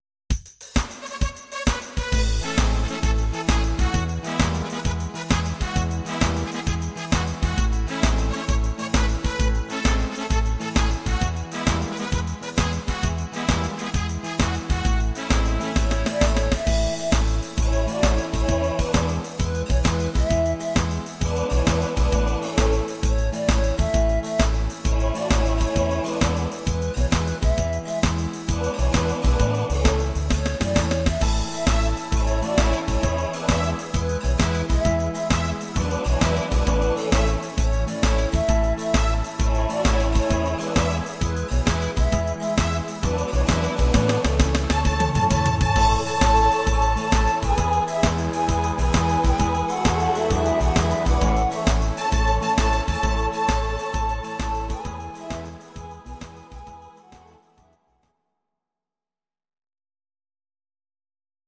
These are MP3 versions of our MIDI file catalogue.